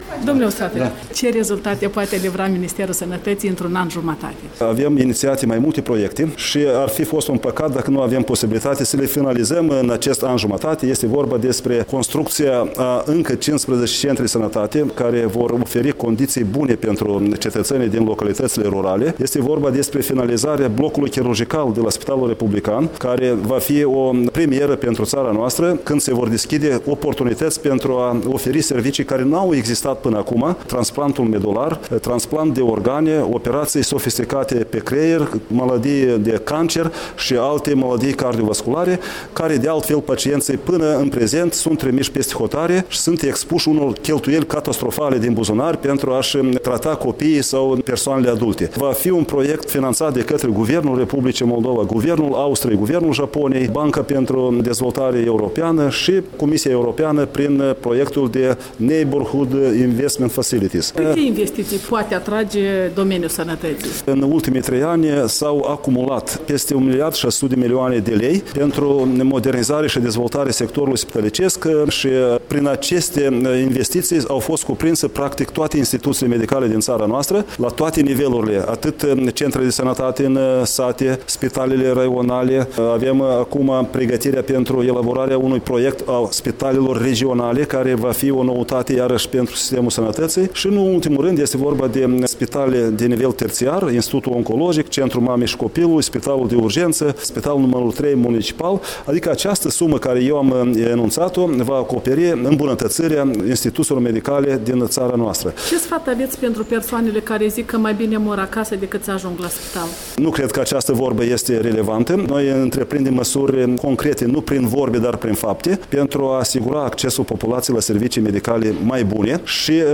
Interviu cu ministrul sănătăţii Andrei Usatîi